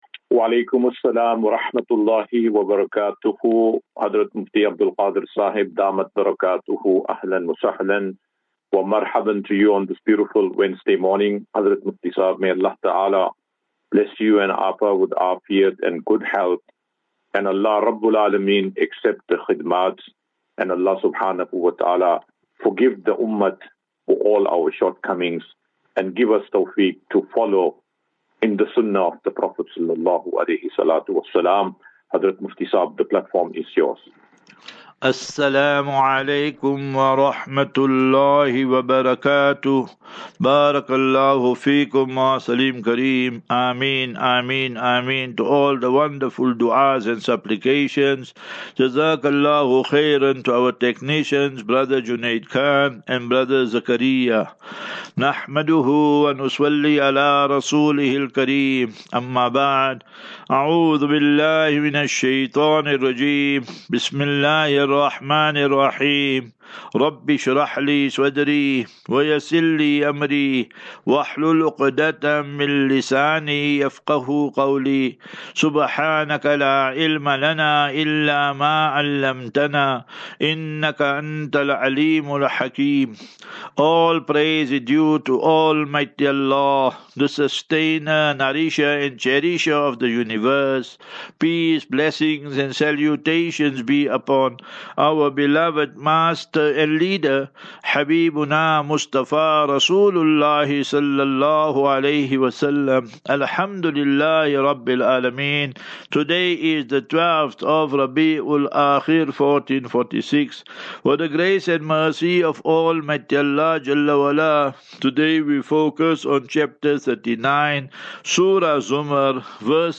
Assafinatu - Illal - Jannah. QnA